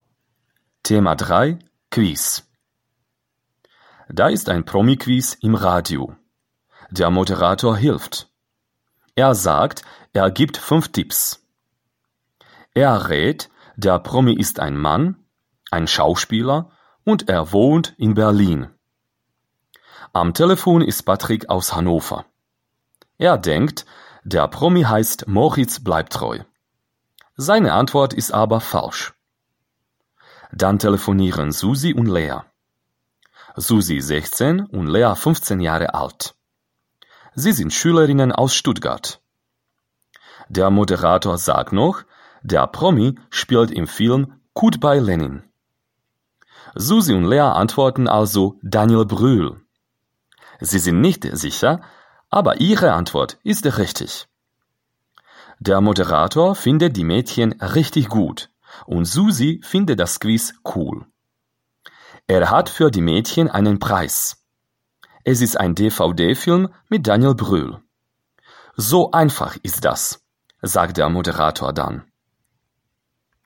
Quiz